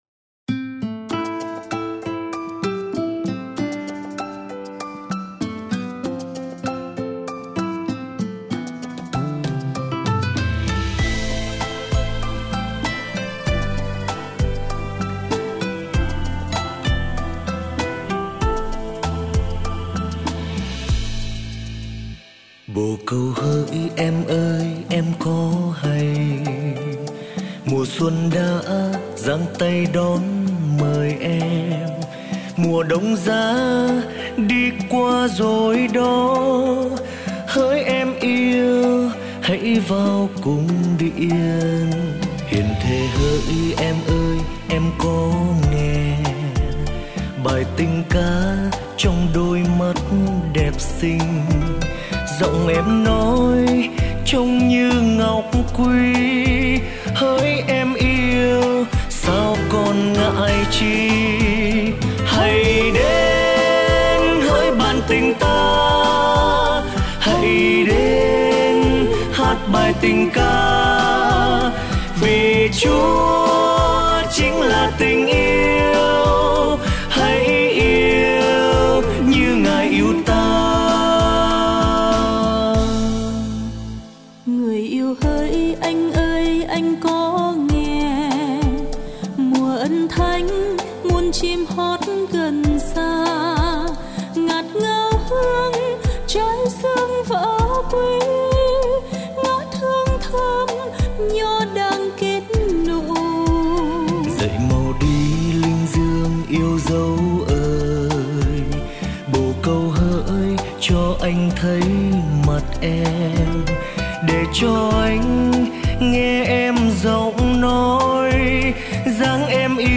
thuộc chuyên mục Lễ Cưới